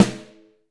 LOW RING.wav